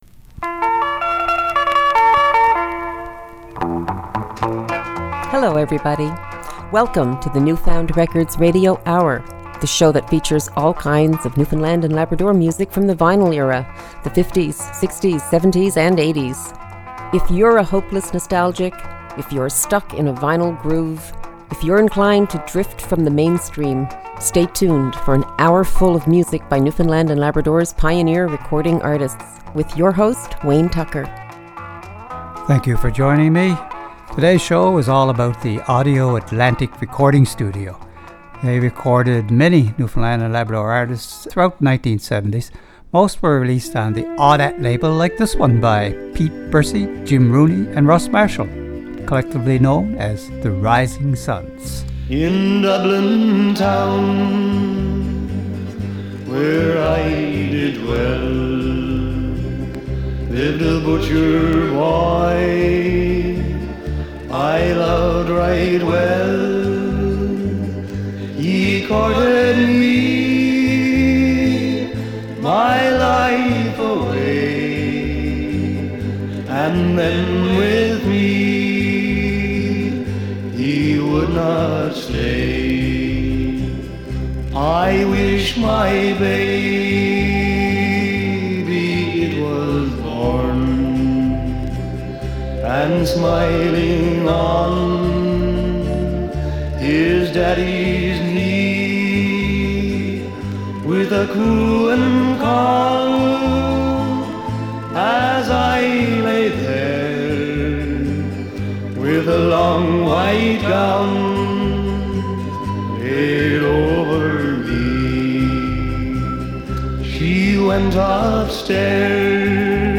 Recorded at CHMR studios, MUN, St. John's, NL.